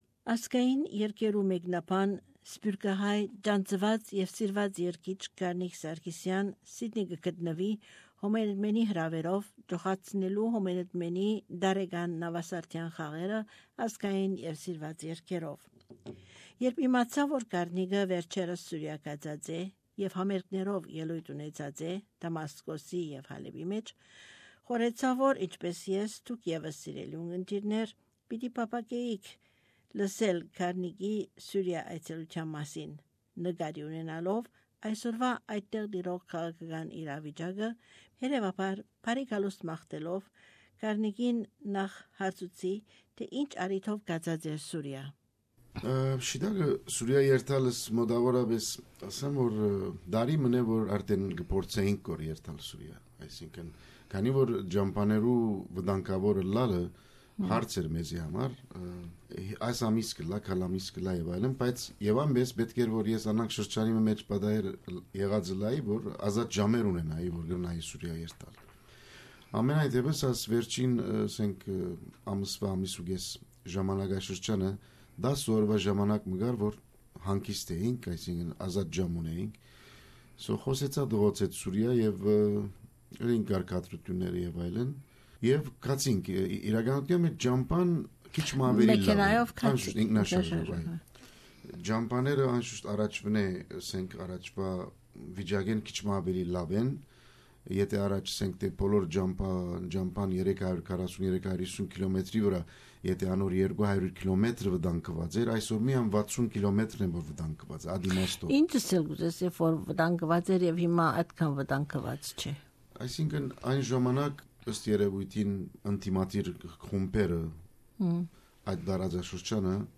Interview with singer Karnig Sarkissian
An in depth interview about the current lifestyle of the Armenian community - or what is left of it - in Syria.